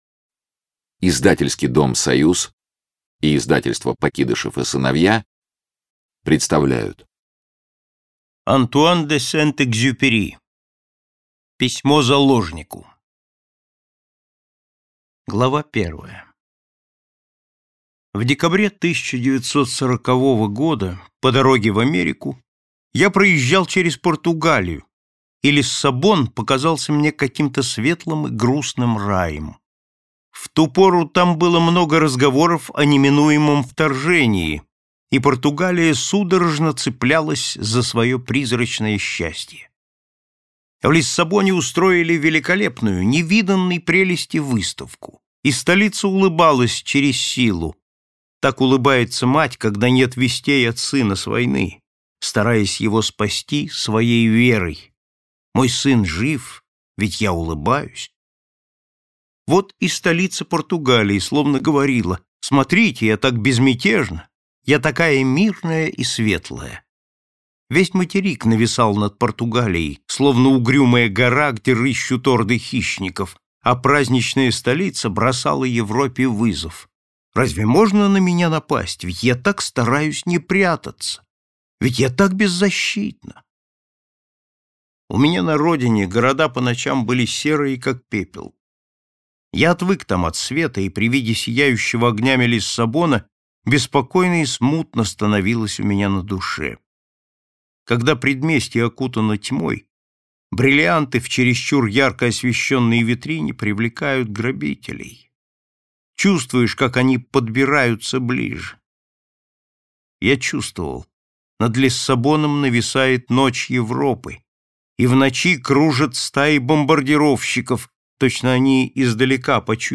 Аудиокнига Письмо заложнику | Библиотека аудиокниг